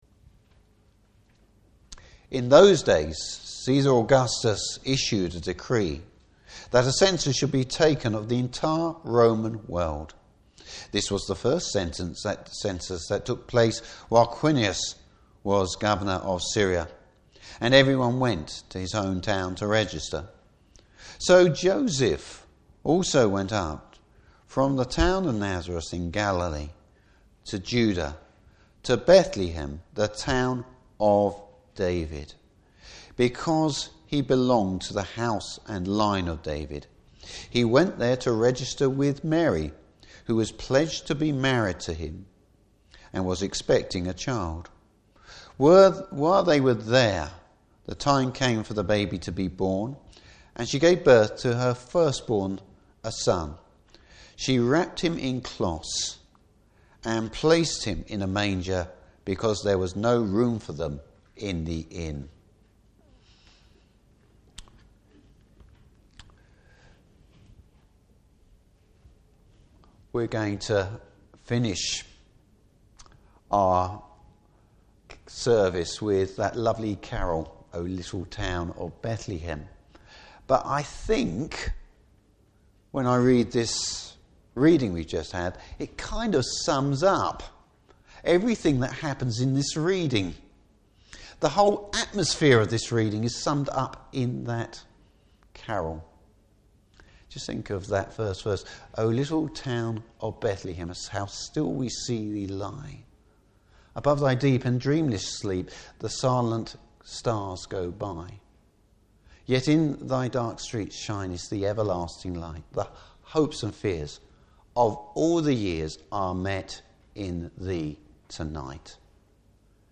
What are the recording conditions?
Service Type: Christmas Eve Service.